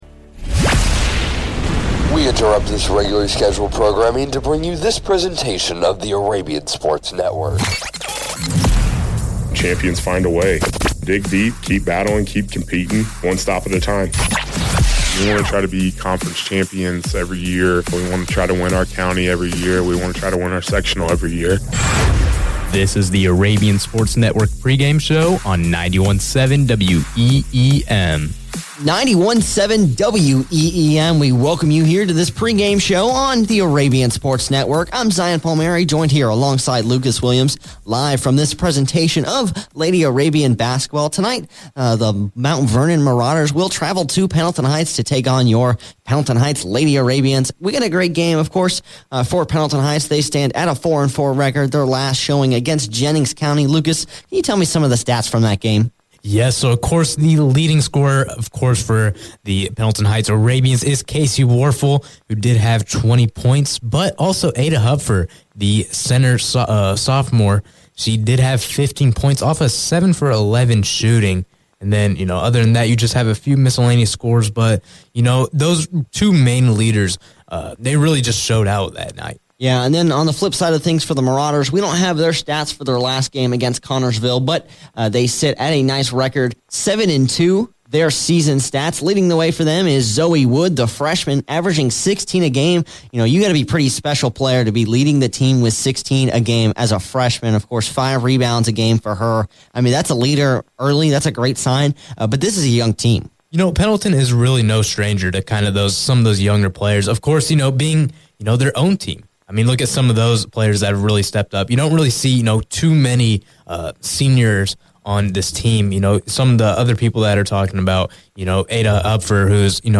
Varsity Girls Basketball Broadcast Replay Pendleton Heights vs. Mount Vernon 12-7-24